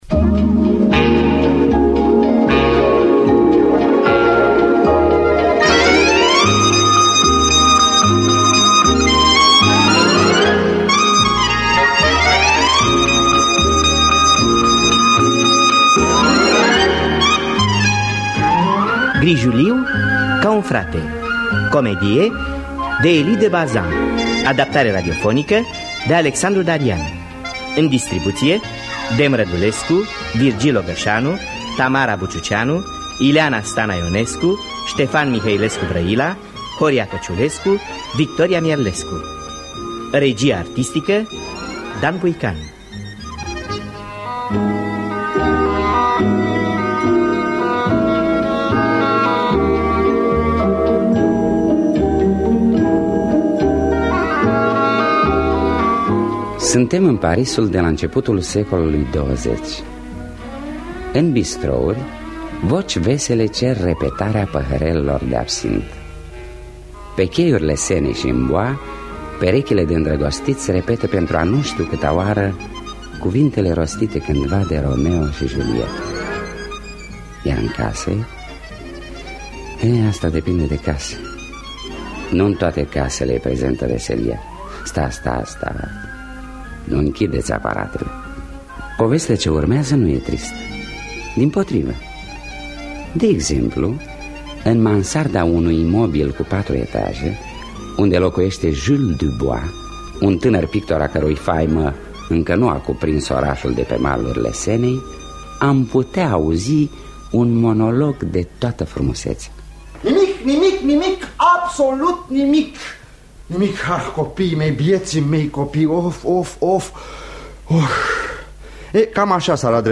Adaptarea radiofonică de Alexandru Darian.